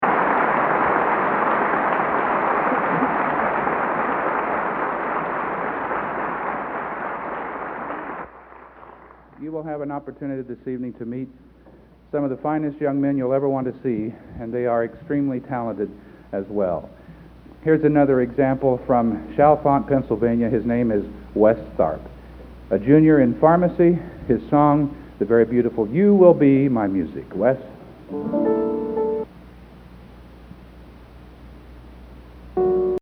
Collection: End of Season, 1987
Location: West Lafayette, Indiana
Genre: | Type: Director intros, emceeing |End of Season